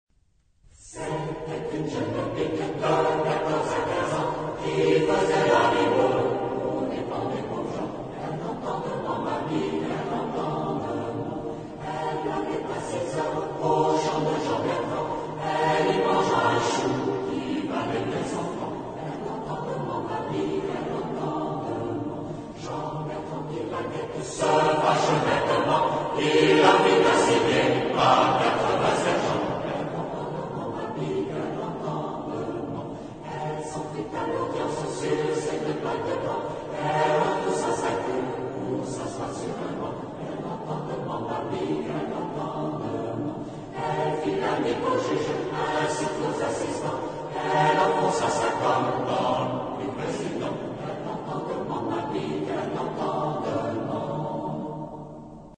Genre-Style-Form: Secular ; Popular
Mood of the piece: moderate
Type of Choir: SATB  (4 mixed voices )
Tonality: E flat lydian